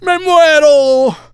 el_primo_death_02.wav